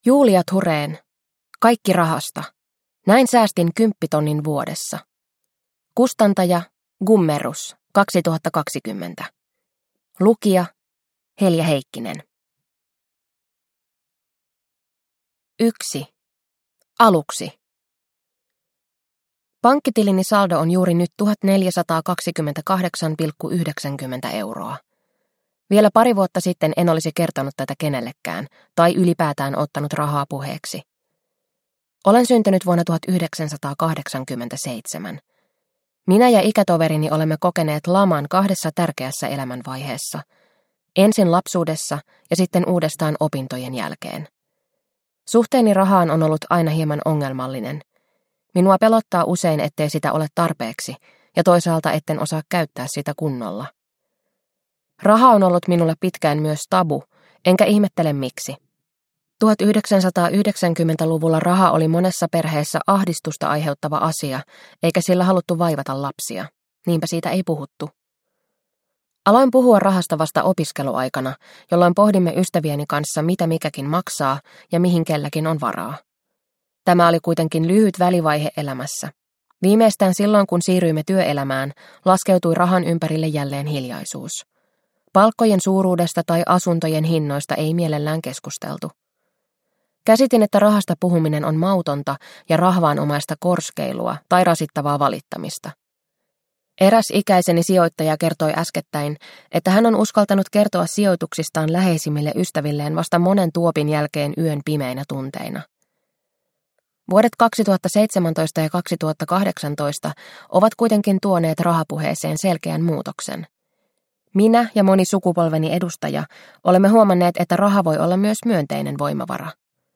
Kaikki rahasta – Ljudbok – Laddas ner